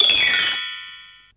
BELLTREEEE-L.wav